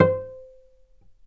vc_pz-C5-mf.AIF